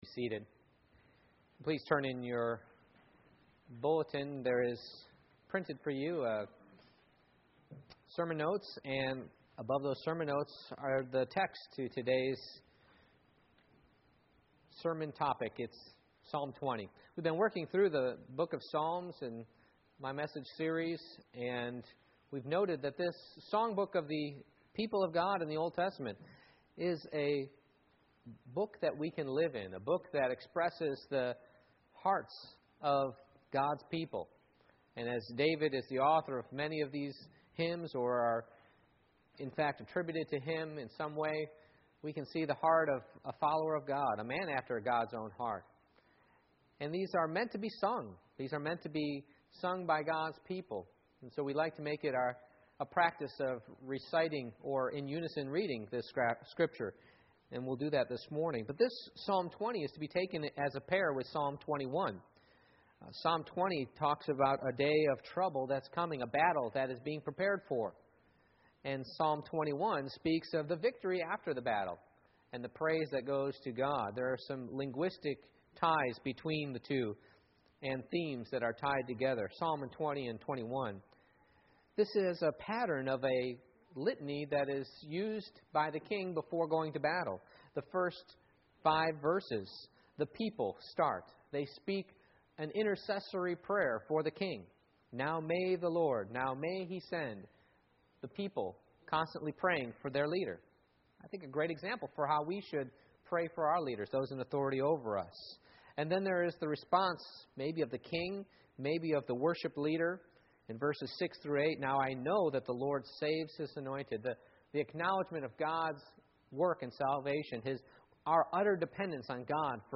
Passage: Psalm 20:1-9 Service Type: Morning Worship